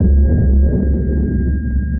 sonarPingSuitMediumShuttle3.ogg